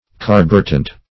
Search Result for " carburetant" : The Collaborative International Dictionary of English v.0.48: Carburetant \Car"bu*ret`ant\, n. Any volatile liquid used in charging illuminating gases.